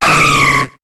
Cri de Cadoizo dans Pokémon HOME.